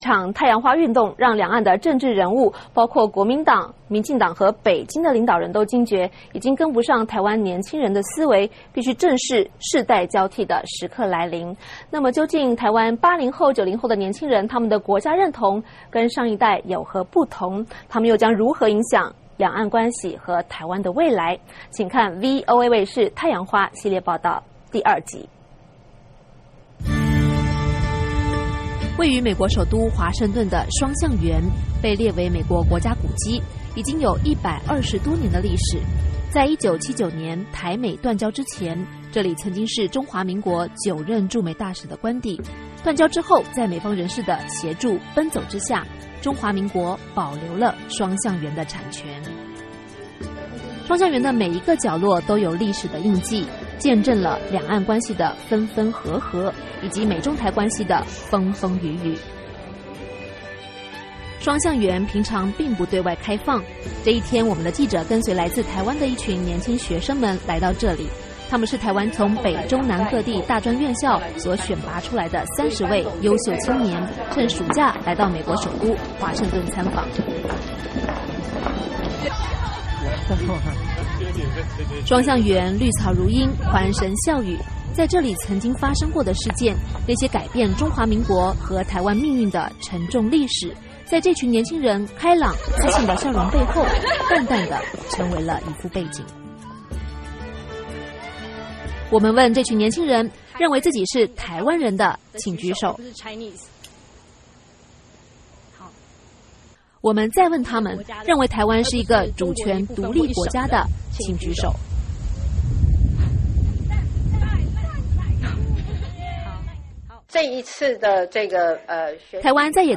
台湾的太阳花运动似乎让两岸的政治人物，包括国民党、民进党和北京的领导人都惊觉，已经跟不上台湾年轻一代的思维，必须正视“世代交替”的时刻来临，那么究竟台湾80后和90后的国家认同跟上一代有何不同，他们又将如何影响两岸关系和台湾的未来?请看VOA卫视太阳花系列报道第二集。